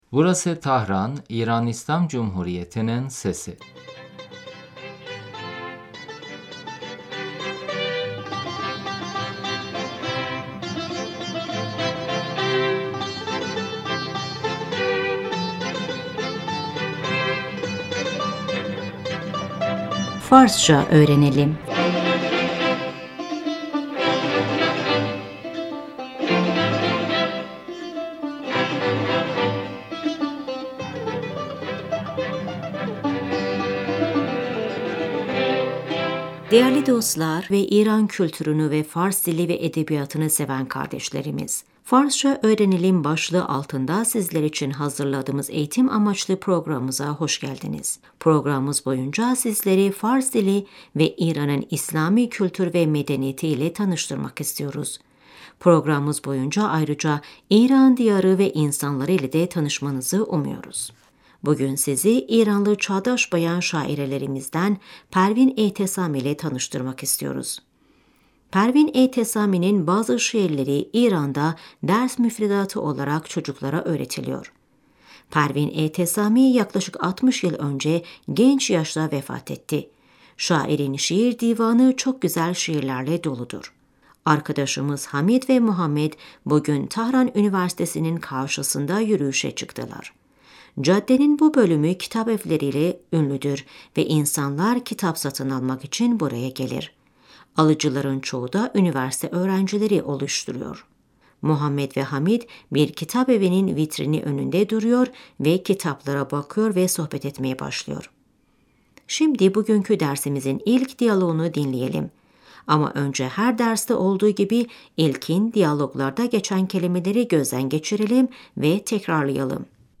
Şimdi bu diyaloğu dinleyin ve tekrarlayın. صدای عبور و مرور خودرو ها در خیابان Caddede trafik sesi حمید - محمد تو به شعر فارسی علاقه داری ؟